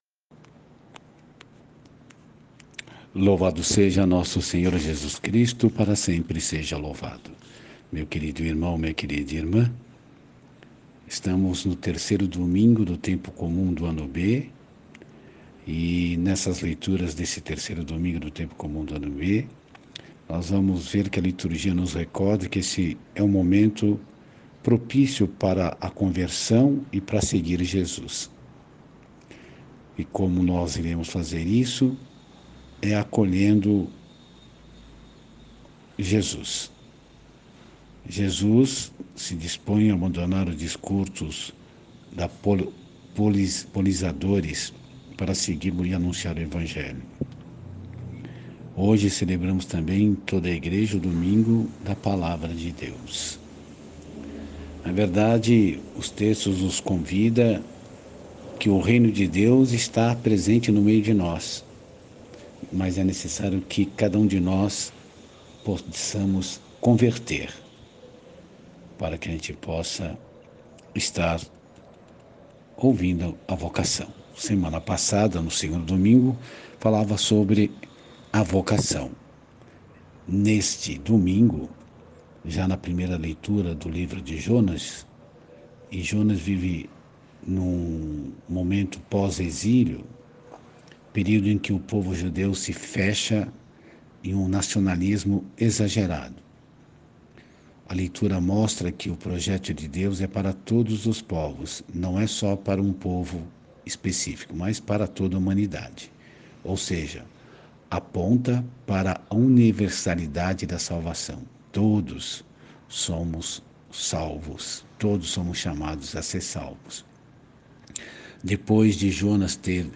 Reflexão e Meditação do 3 Domingo do Tempo Comum. Ano B